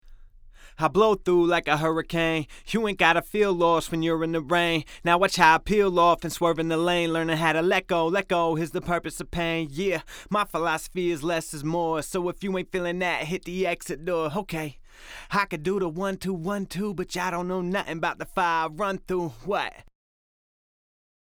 Here are some audio examples of a vocal performance with and without compression in the Recording Phase.
no compression
The performance without compression starts off really loud and then gets much quieter towards the end, which will present some problems further along in the mixing process.
ex_1_no_compression.mp3